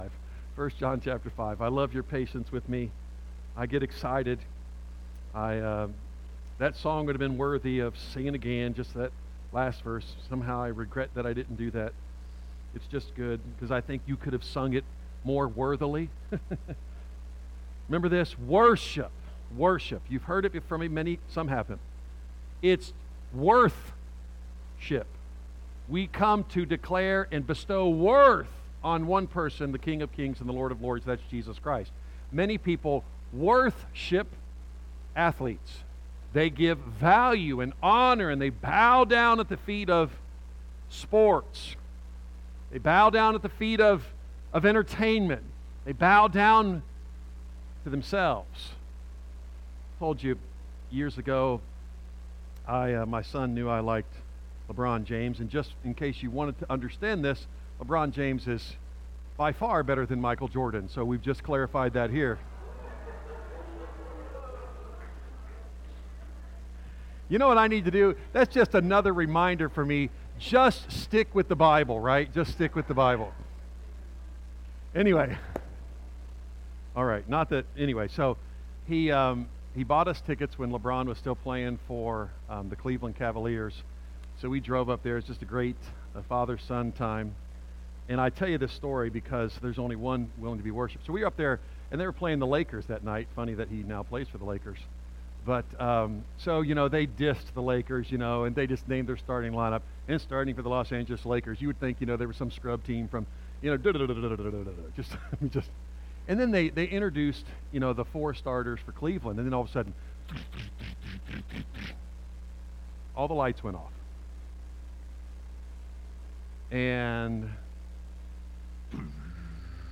A message from the series "I John."